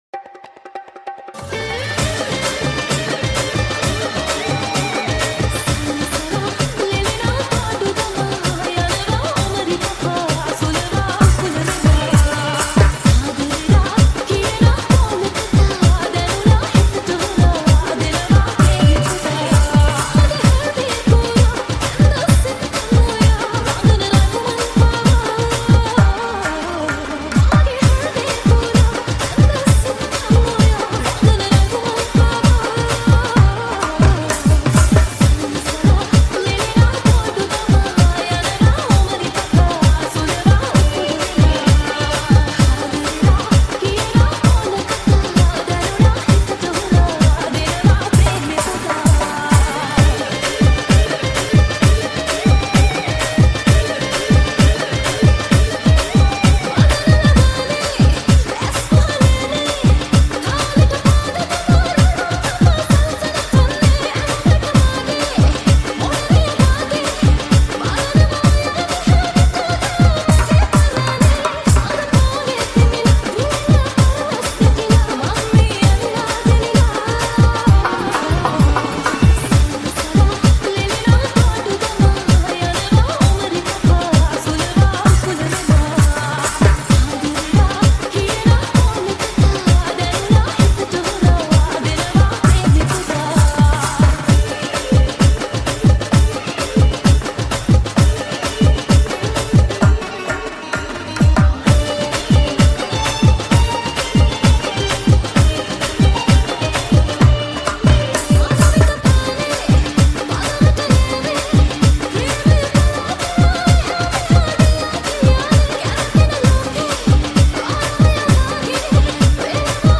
High quality Sri Lankan remix MP3 (3).